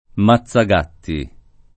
maZZag#tti] o mazzagatto [maZZag#tto] s. m.; pl. -ti — voce antiq. per «pistola corta» — sim. il top. Mazzagatta, nome fino al 1970 di Mazzantica (Ven.), e i cogn. Mazzagatta, Mazzagatti